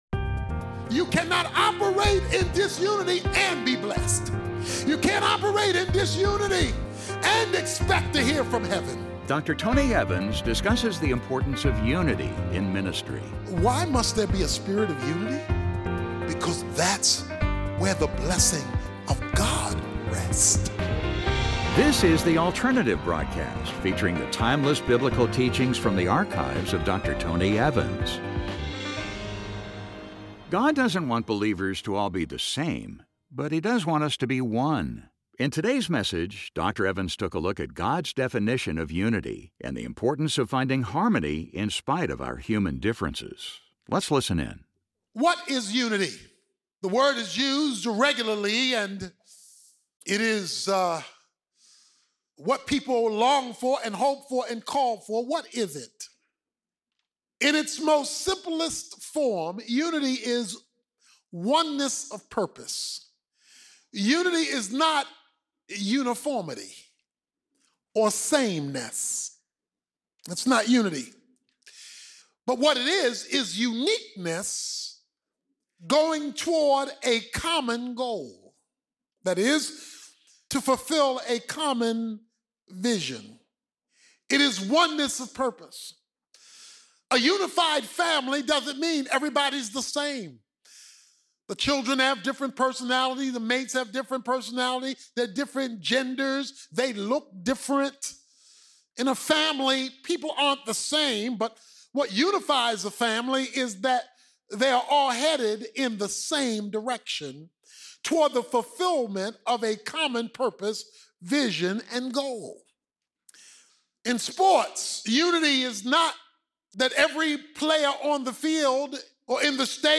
God doesn't want believers to all to be the same, but He does want us to be one. In this classic message, Dr. Tony Evans takes a look at God's definition of unity and the importance of finding harmony in spite of our human differences.